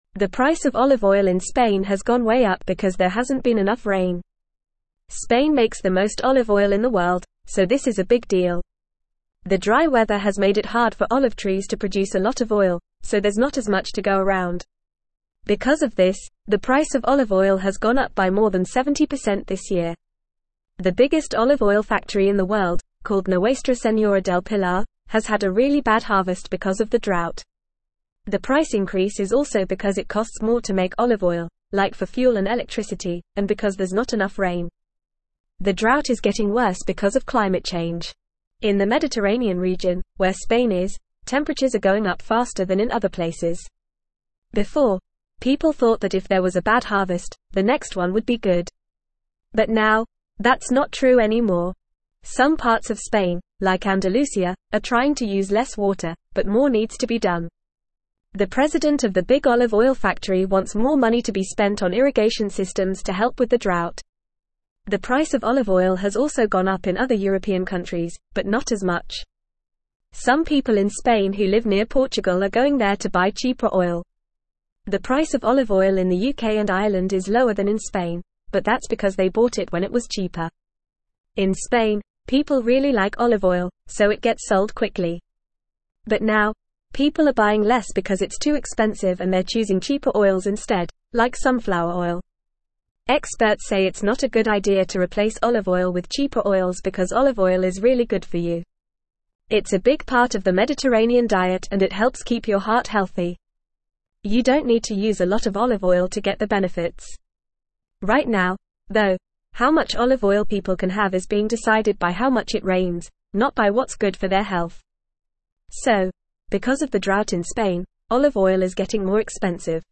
Fast
English-Newsroom-Upper-Intermediate-FAST-Reading-Skyrocketing-Olive-Oil-Prices-in-Spain-Due-to-Drought.mp3